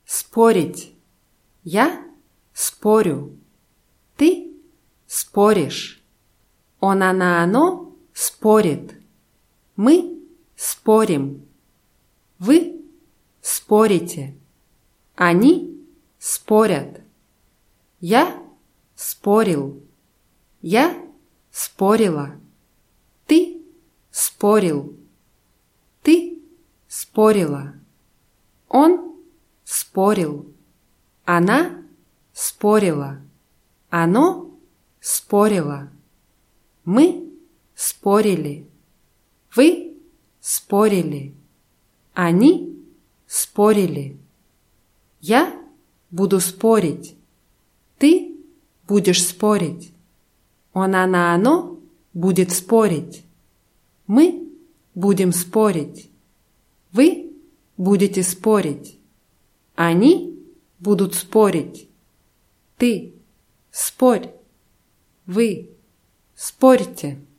спорить [ßpórʲitʲ]